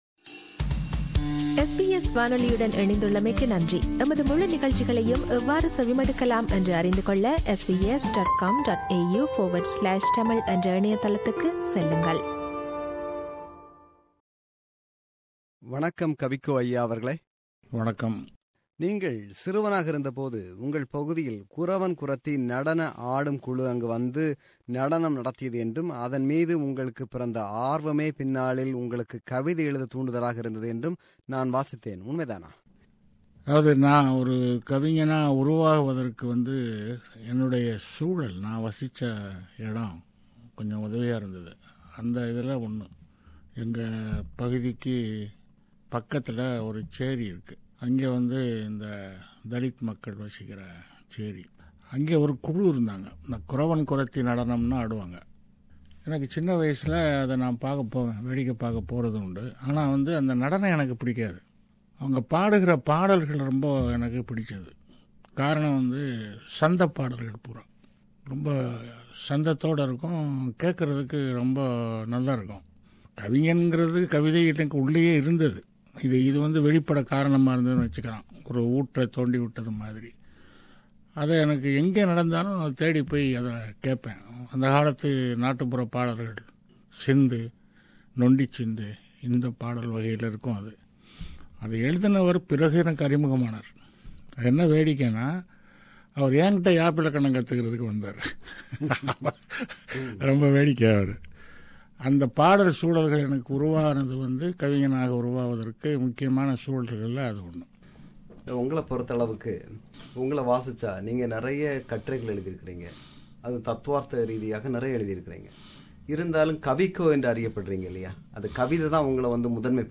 He spoke extensively on various issues including literary work, politics, Thirukkural and religion. Its a re-broadcast of the interview.